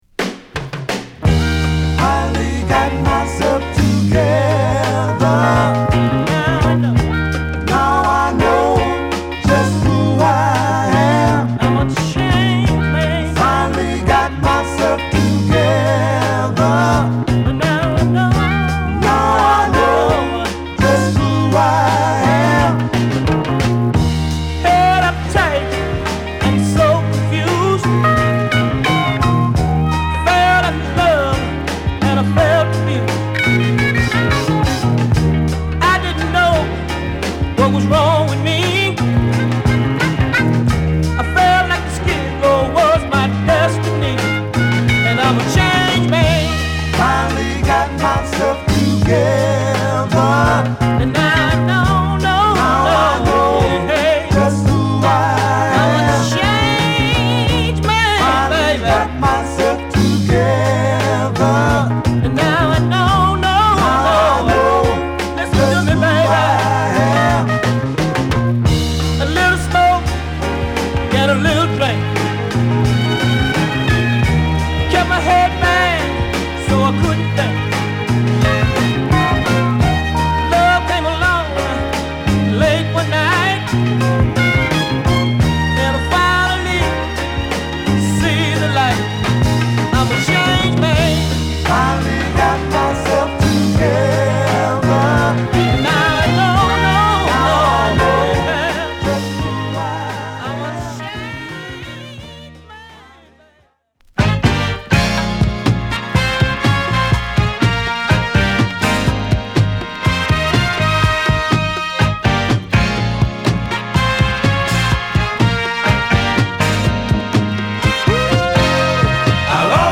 フィリー調のストリングス使いやファンキーなホーン隊もカッコ良い、疾走するグルーヴィーなダンスチューン！